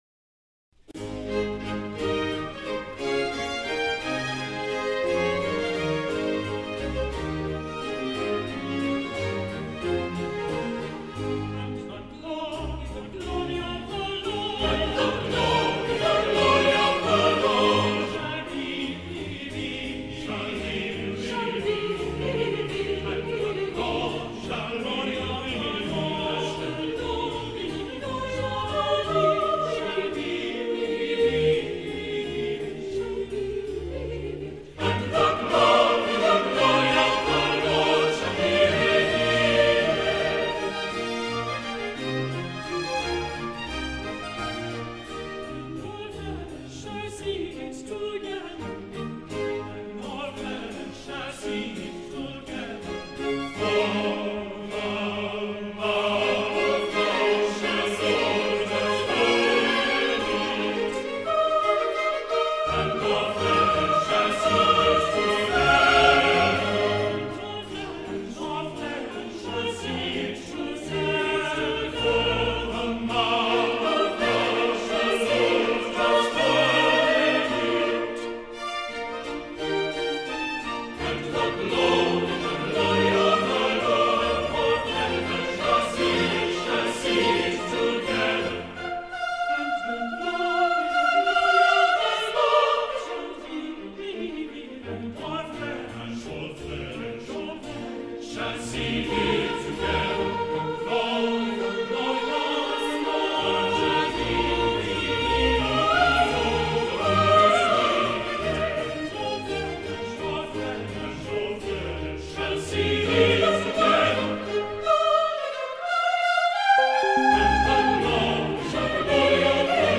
Index of /The Messiah/A Full Choral Examples